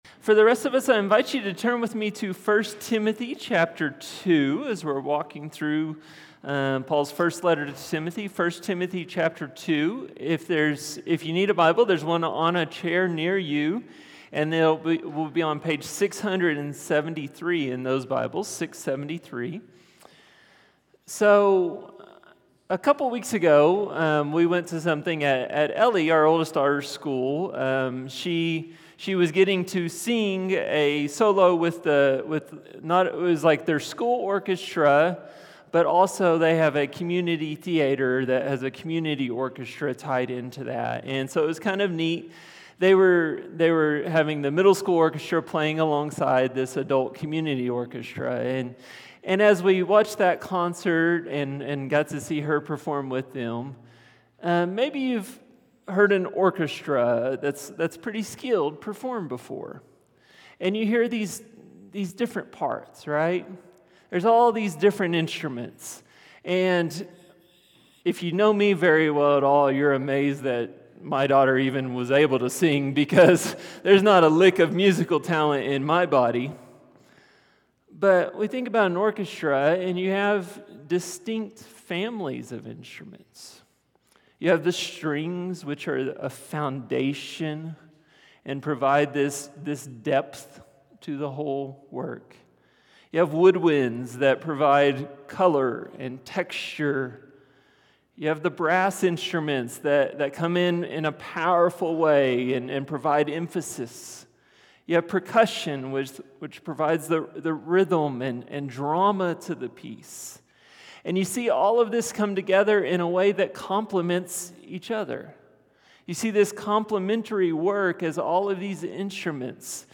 Sermons | HopeValley Church // West Jordan, UT